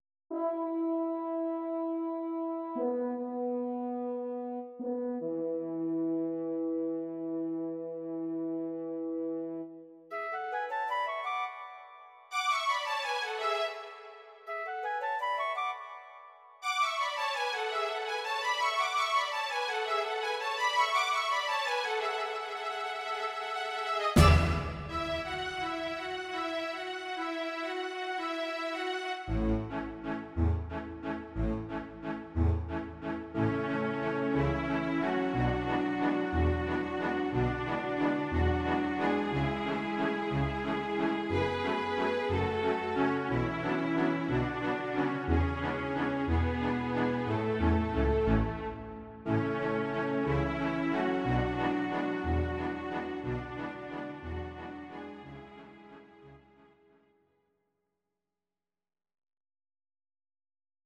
These are MP3 versions of our MIDI file catalogue.
Please note: no vocals and no karaoke included.
Your-Mix: Traditional/Folk (1155)
instr. Orchester